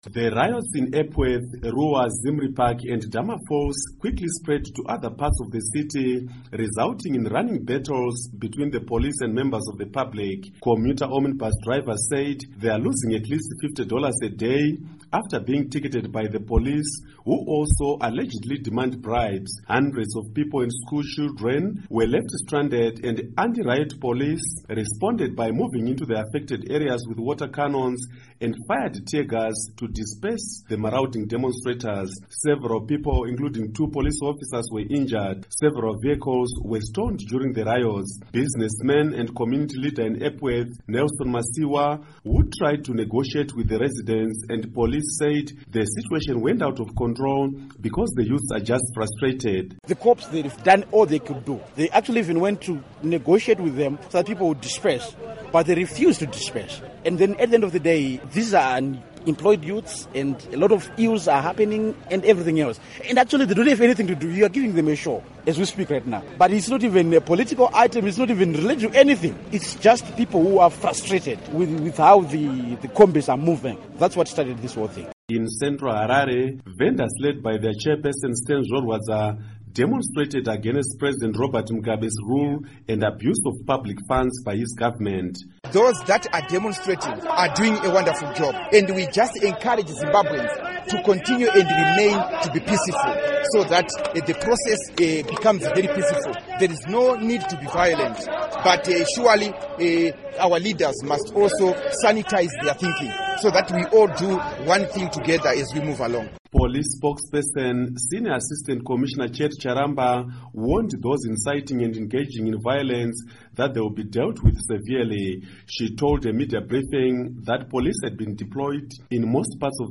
Report on Harare Riots